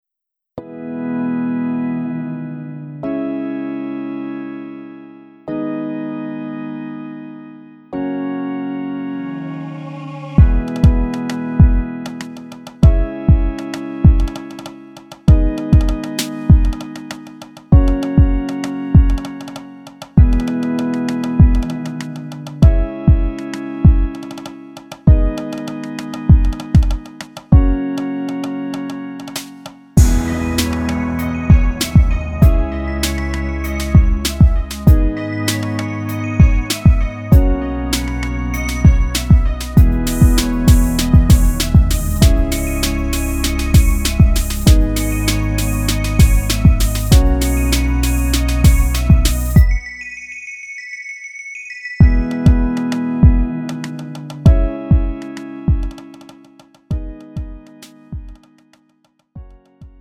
음정 -1키 2:35
장르 가요 구분 Lite MR